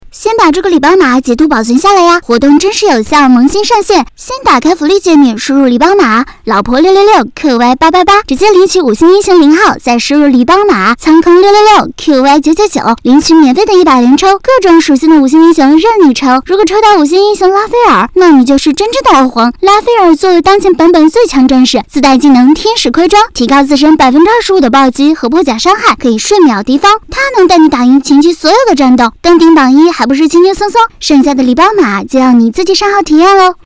游戏角色